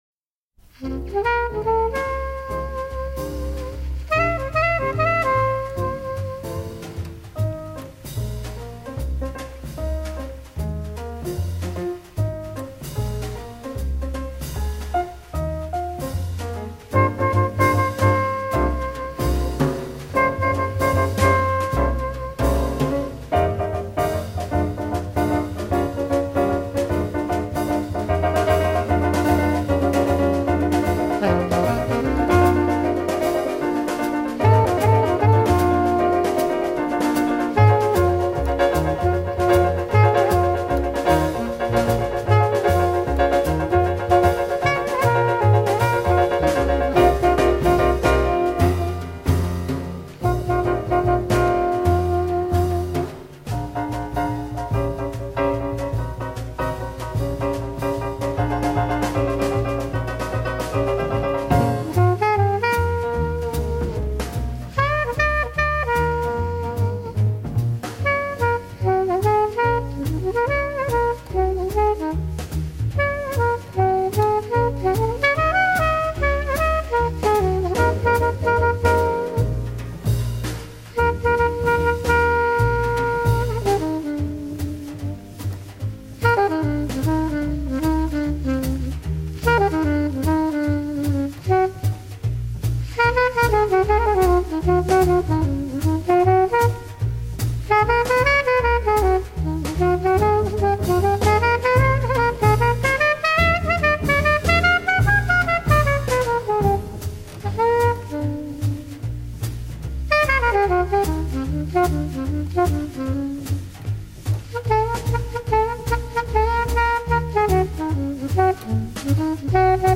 这张专辑中八分之九拍、四分之五拍、四分之六拍等诸多非常规爵士乐节拍也有出现，